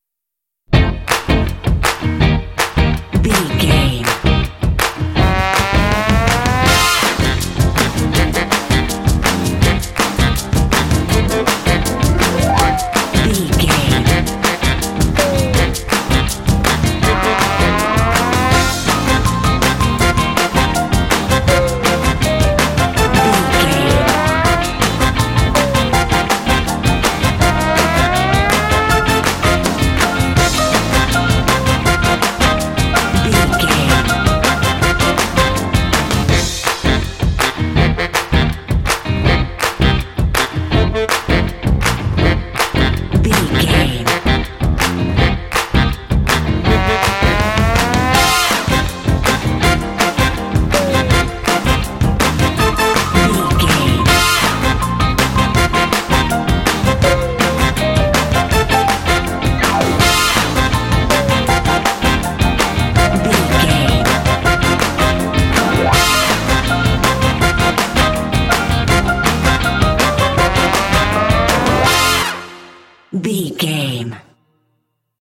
Uplifting
Mixolydian
funky
smooth
groovy
driving
positive
bass guitar
brass
piano
drums
percussion
electric guitar
Funk
soul